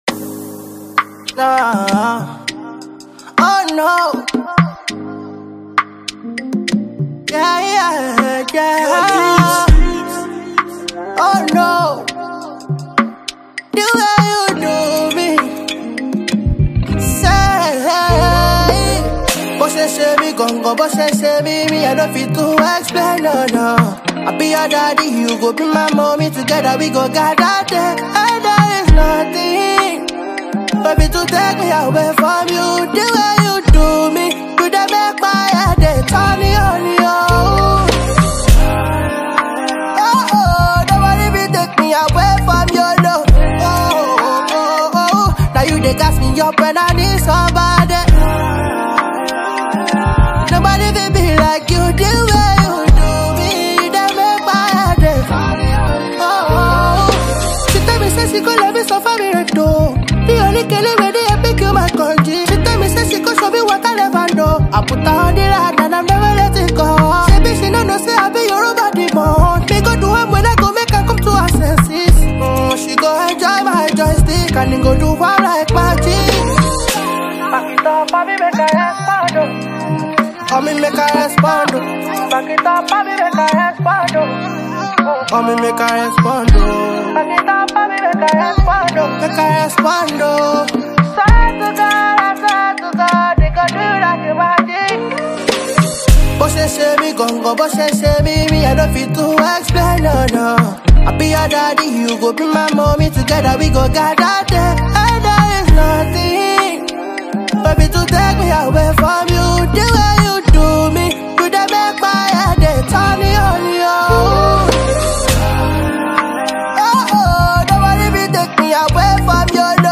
chilling song